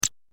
دانلود آهنگ موش 1 از افکت صوتی انسان و موجودات زنده
دانلود صدای موش 1 از ساعد نیوز با لینک مستقیم و کیفیت بالا
جلوه های صوتی